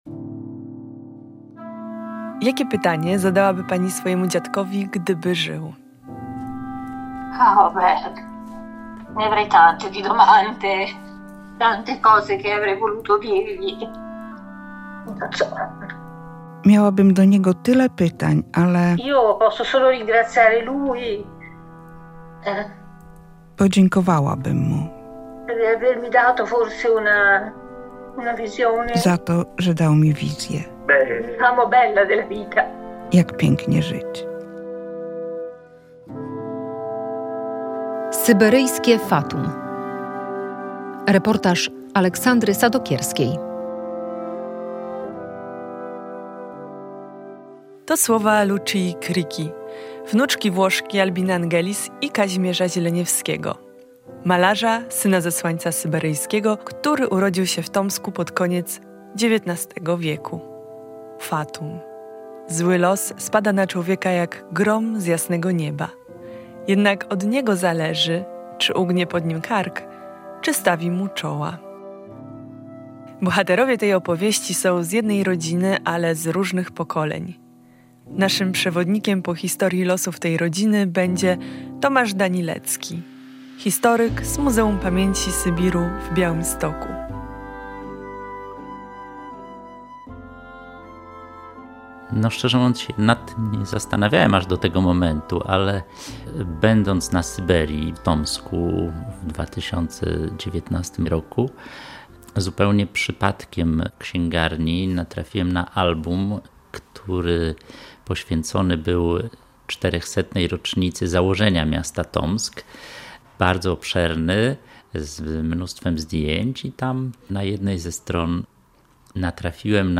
Radio Białystok | Reportaż | "Syberyjskie fatum"